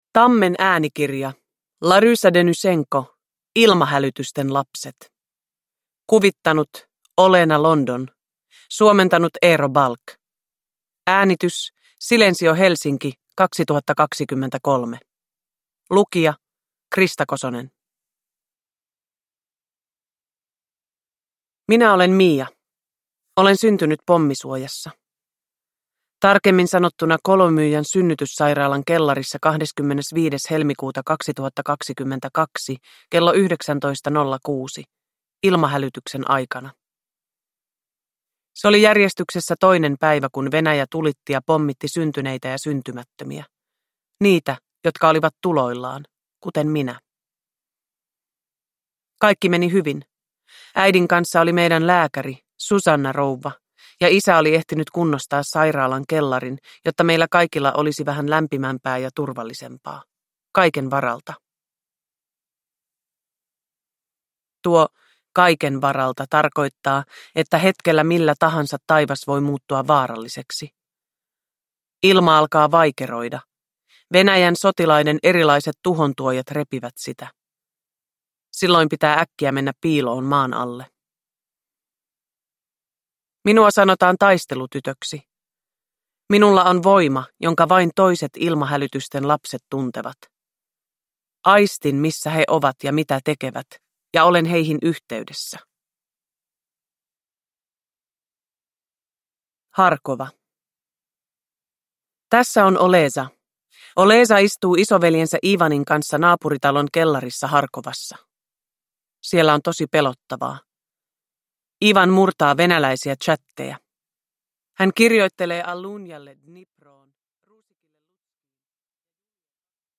Ilmahälytysten lapset – Ljudbok – Laddas ner
Uppläsare: Krista Kosonen